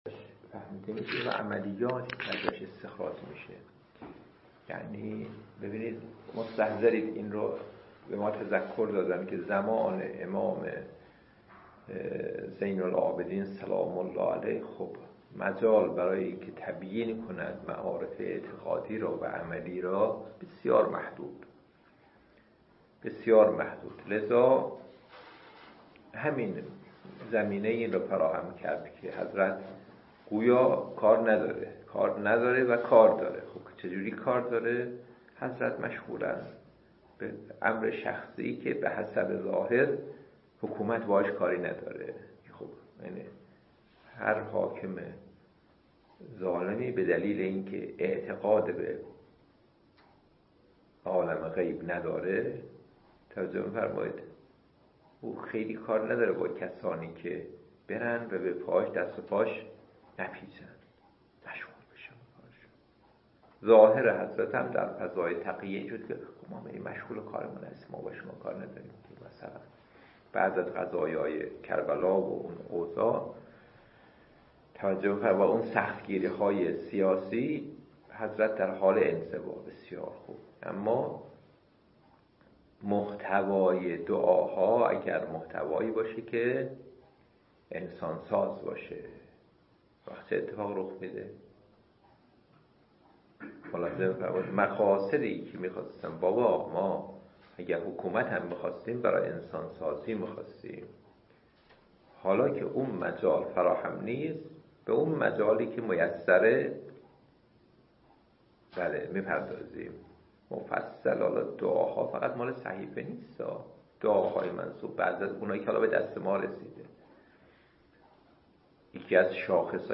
درس الاخلاق